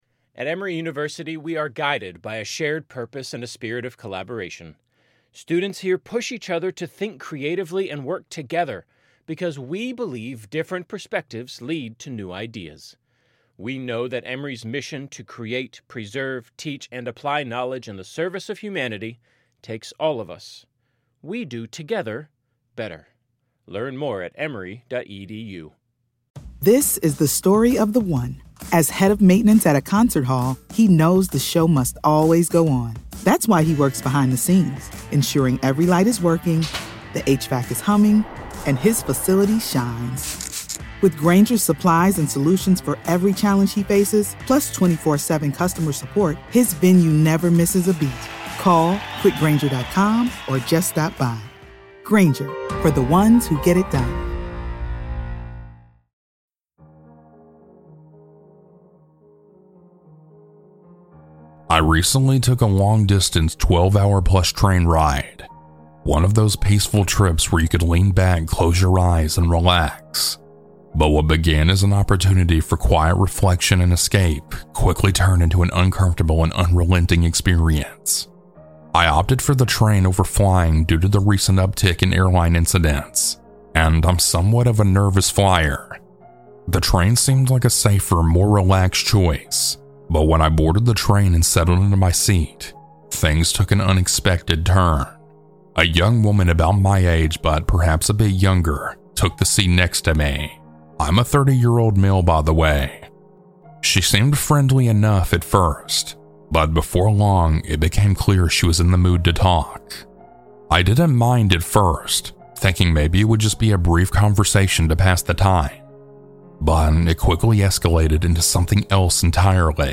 I've never been on a Train before and I'm not sure I ever want to after narrating these stories...
Huge Thanks to these talented folks for their creepy music!